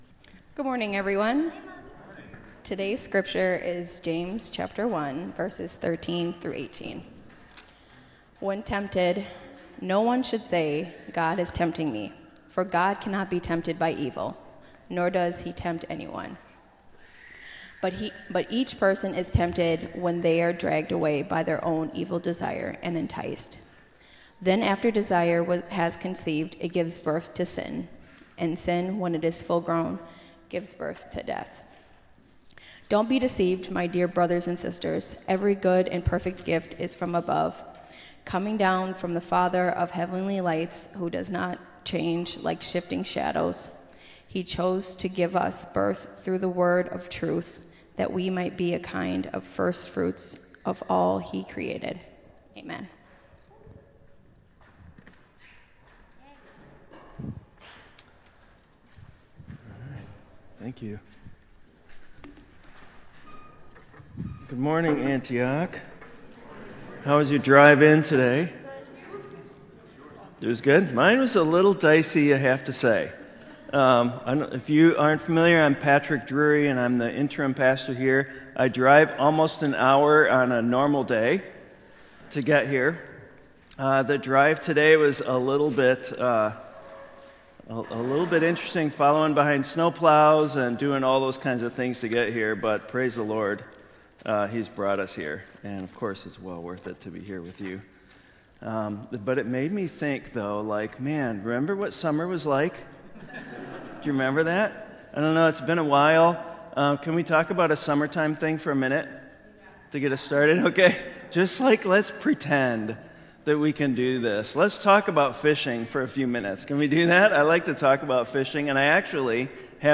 sermon-james-gone-fishing.m4a